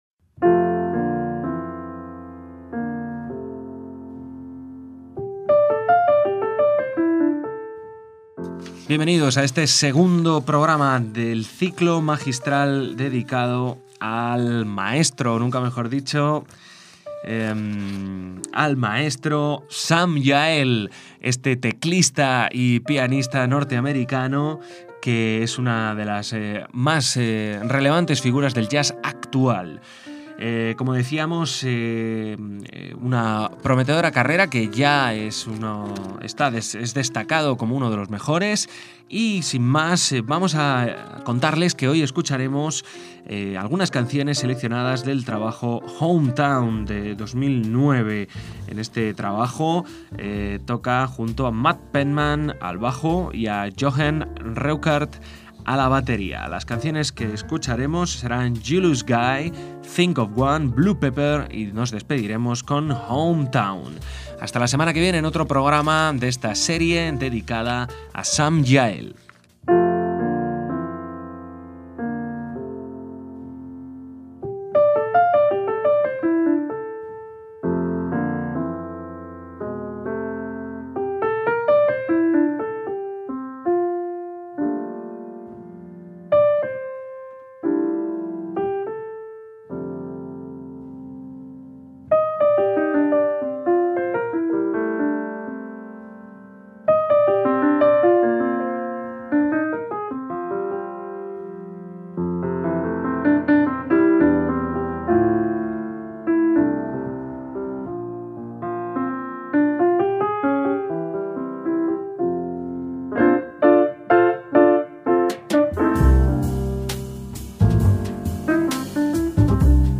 bajo
batería